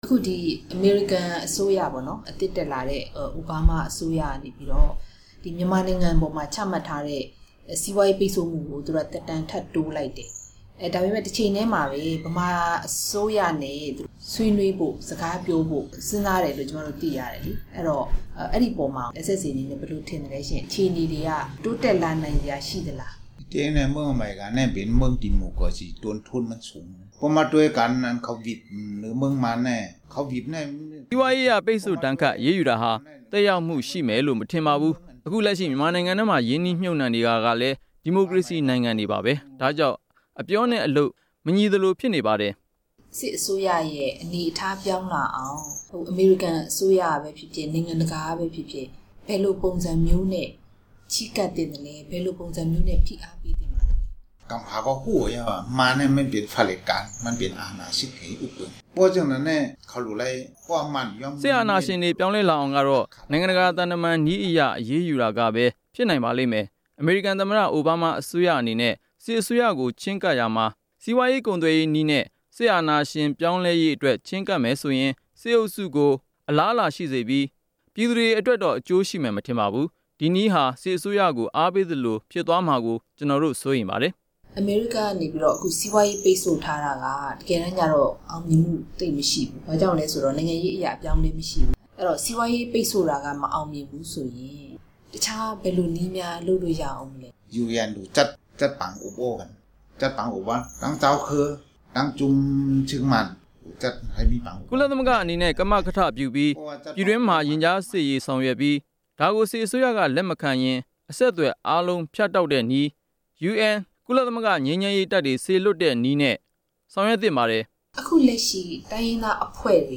ရြမ်းူပည်တောင်ပိုင်း တပ်မတော် SSA ခေၝင်းဆောငိံြင့် တြေႚဆုံမေးူမန်းခန်း